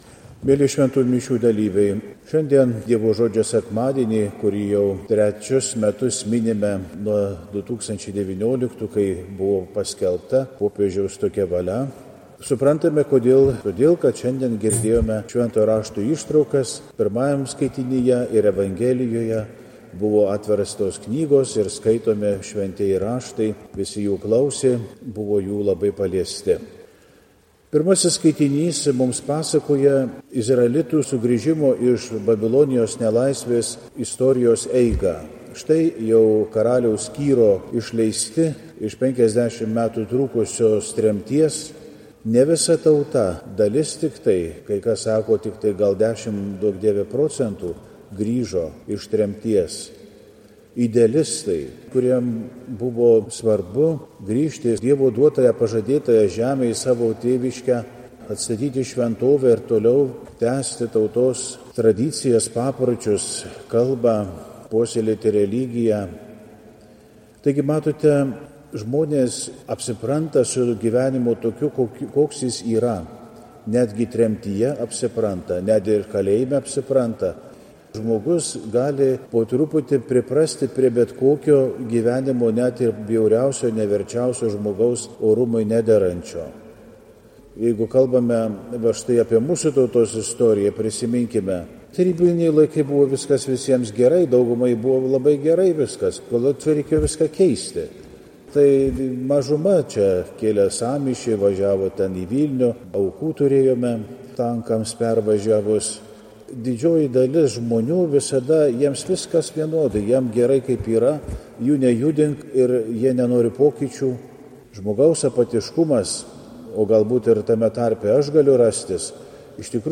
Pamokslai